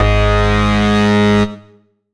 VES2 Bass Shots
VES2 Bass Shot 186 - G#.wav